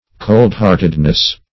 cold-heartedness \cold"-heart`ed*ness\ n.